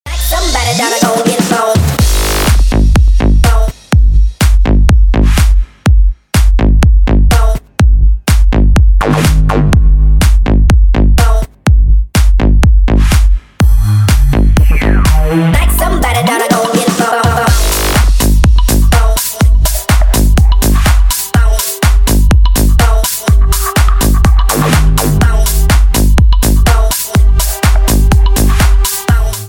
• Качество: 128, Stereo
Качающая песенка на звонок